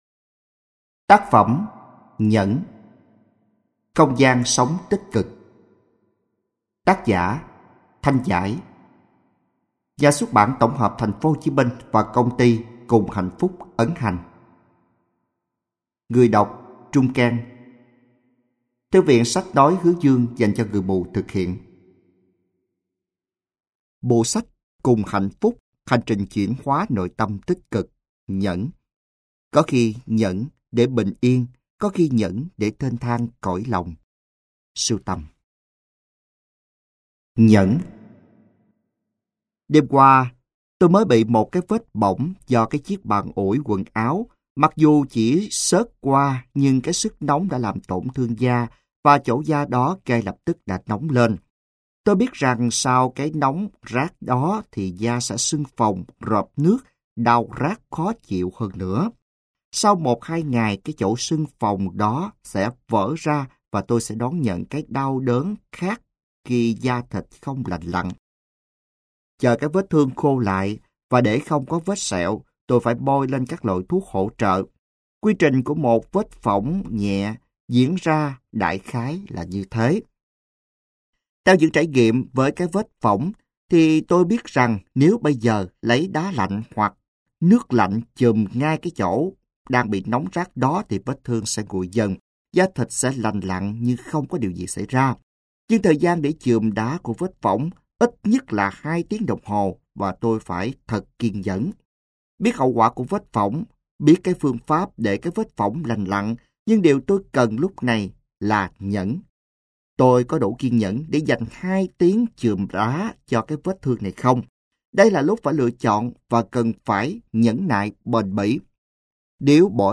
Sách nói Nhẫn - Không gian sống tích cực - Hạt Giống Tâm Hồn - Sách Nói Online Hay